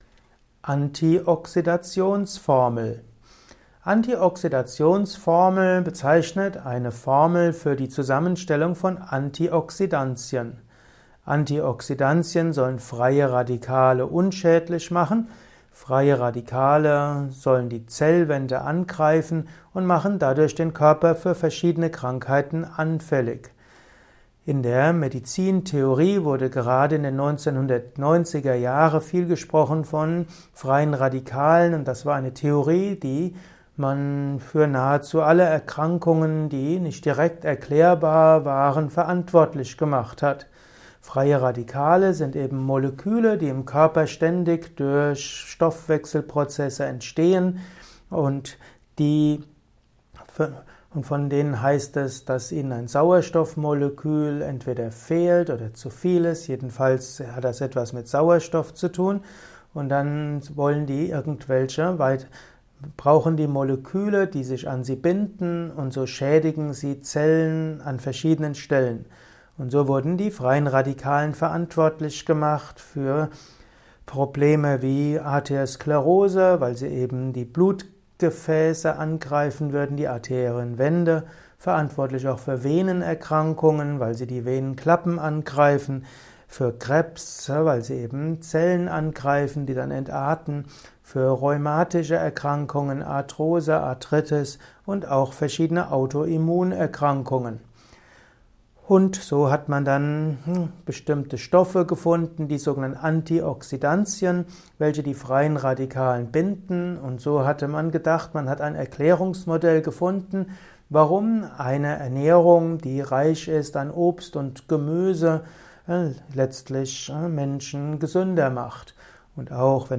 Informationen zu der Antioxidationsformel in diesem Kurzvortrag